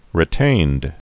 (rĭ-tānd)